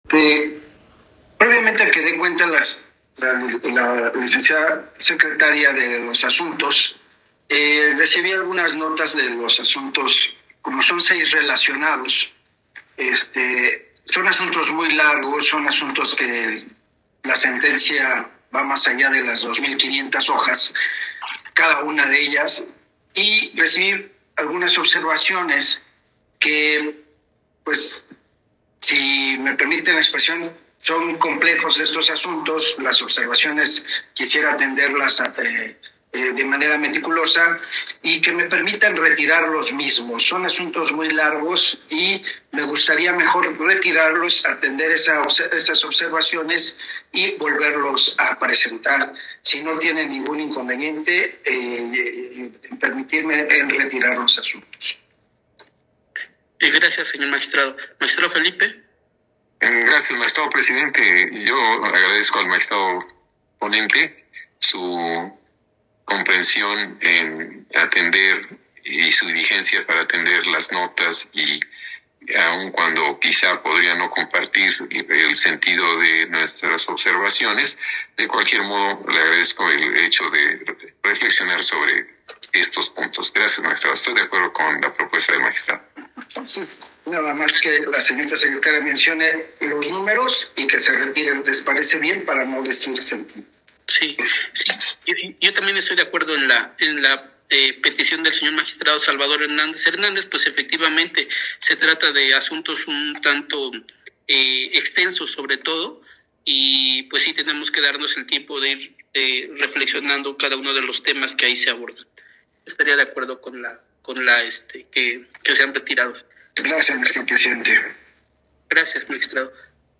AUDIO EN EL QUE MAGISTRADOS DESECHAN LOS AMPAROS PARA RESOLUCIÓN ESTE JUEVES 13 DE ABRIL DE 2023